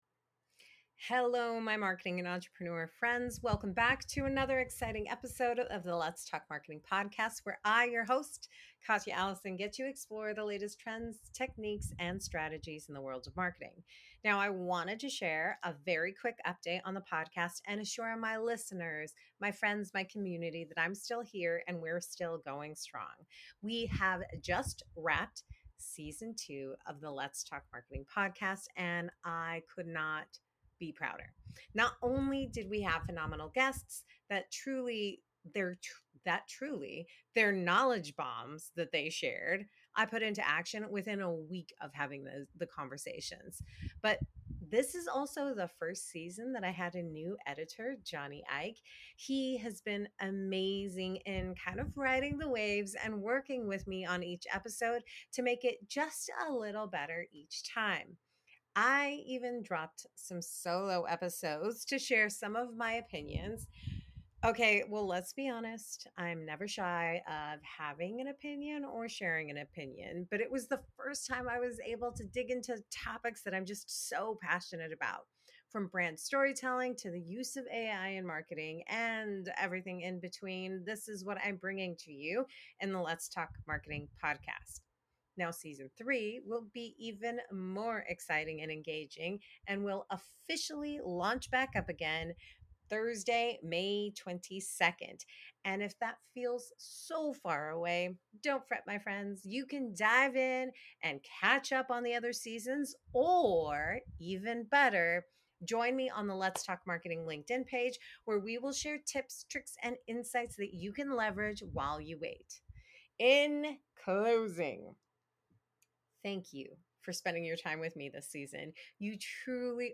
The conversation delves into how to integrate authenticity into marketing efforts, tap into creative energy, and the importance of aligning with your true self in your business.